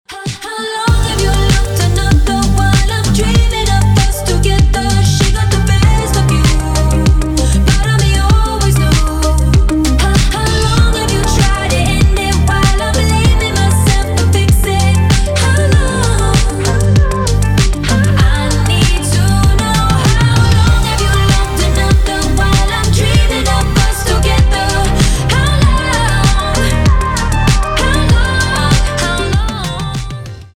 • Качество: 320, Stereo
Synth Pop